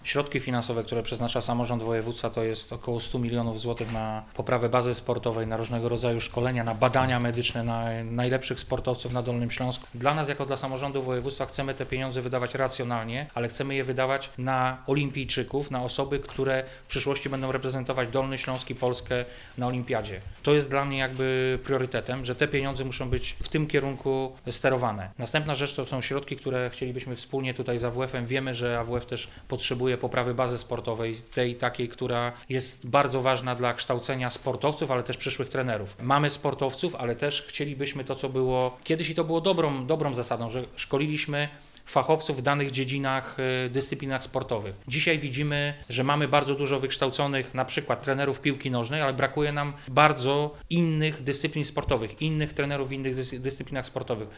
O polach, na jakich Urząd Marszałkowski i AWF będą współpracować mówi wicemarszałek Wojciech Bochnak.